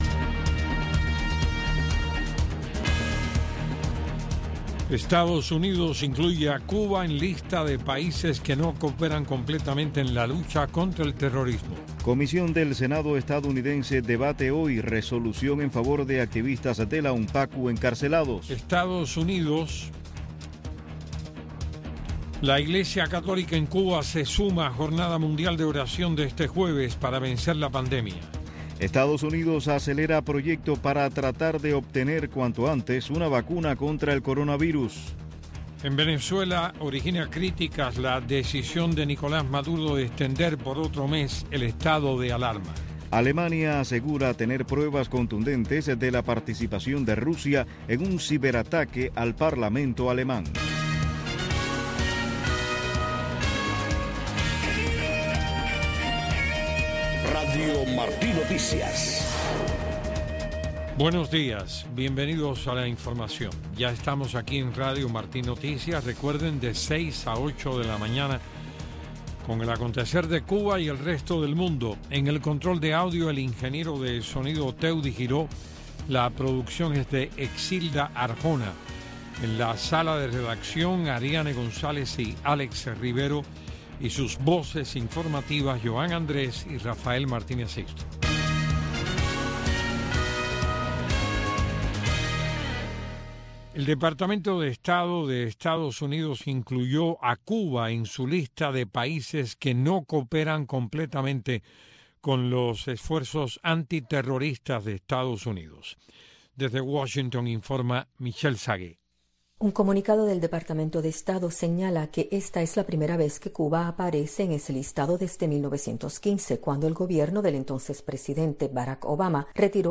Noticiero de Radio Martí 6:00 AM